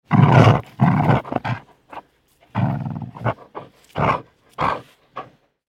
دانلود آهنگ سگ 1 از افکت صوتی انسان و موجودات زنده
دانلود صدای سگ 1 از ساعد نیوز با لینک مستقیم و کیفیت بالا
جلوه های صوتی